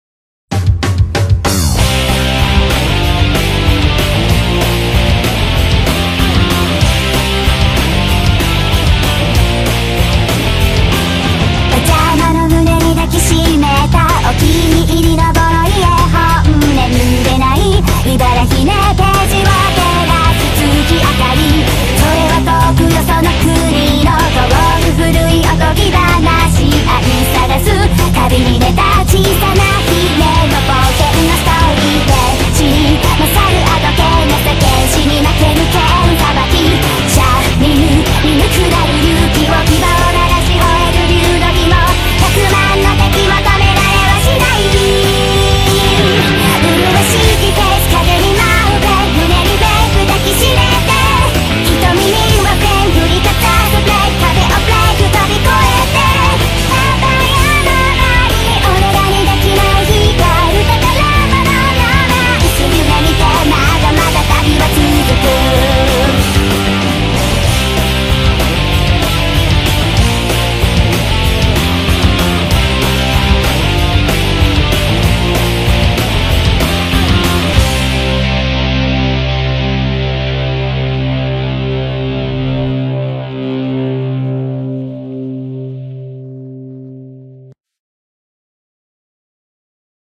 BPM190
Audio QualityCut From Video